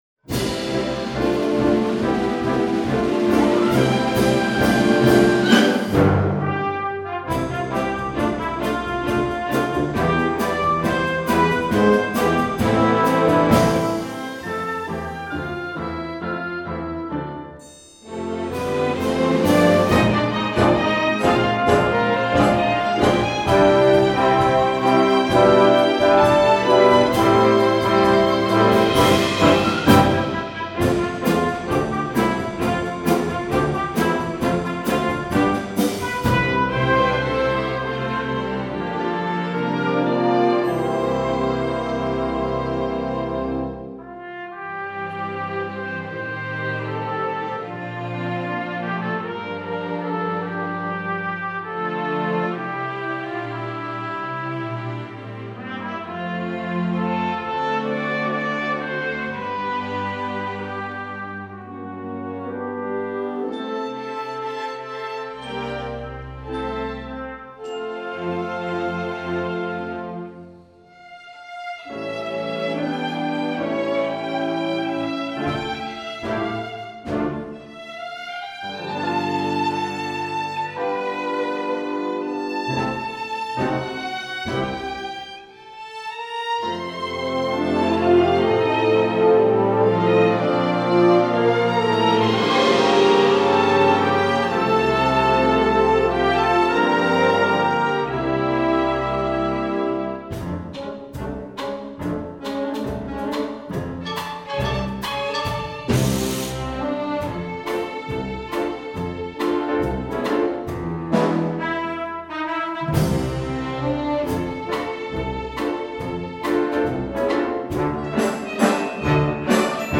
Instrumentation: full orchestra